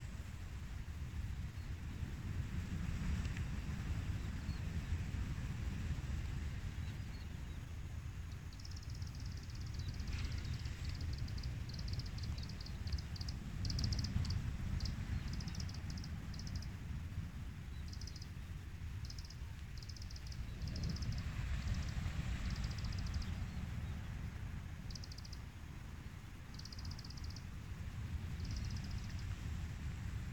海堤兩邊為防風林、內有多年前放置之消波塊，形成樹林中有消波塊的獨特景象。 防風林外即是沙灘，有沙灘車來回奔馳，不利收音。